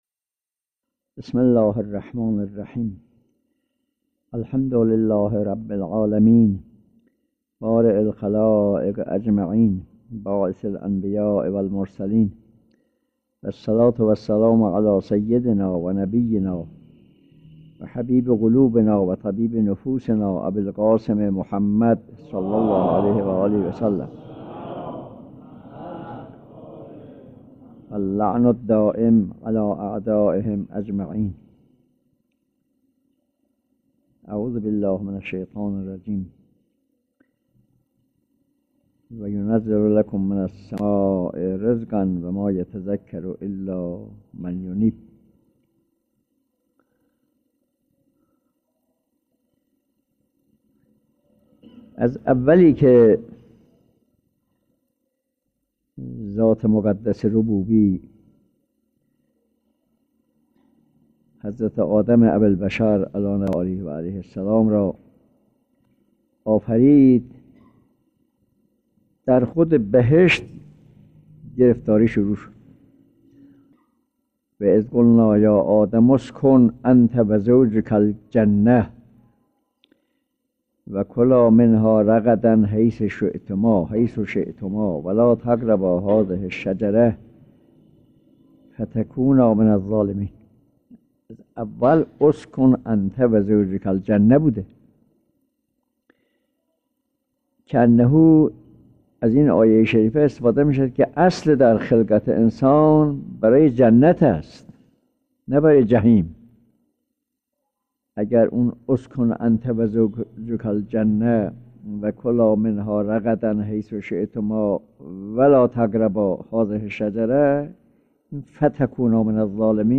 درس اخلاق